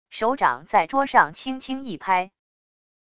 手掌在桌上轻轻一拍wav音频